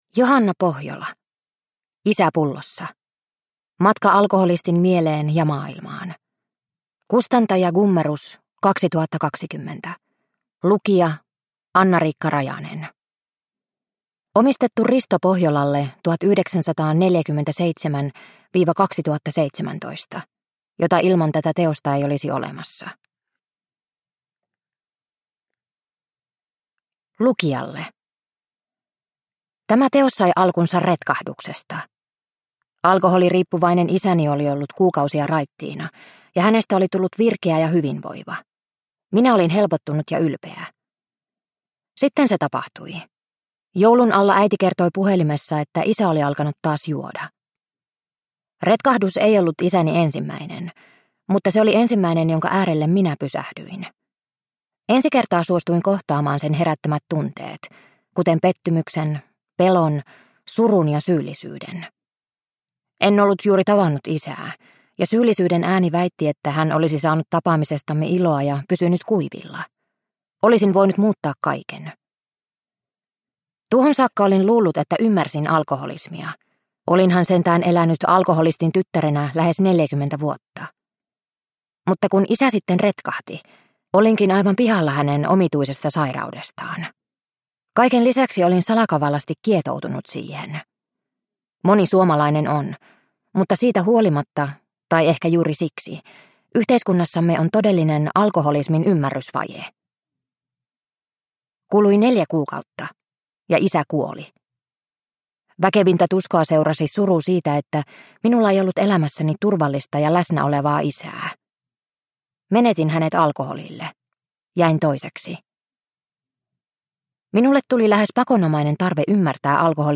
Isä pullossa – Ljudbok – Laddas ner